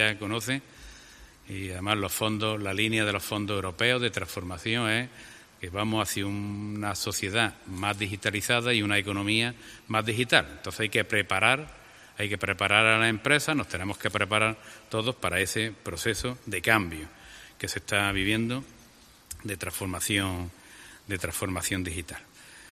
José Entrena, Presidente de la Diputación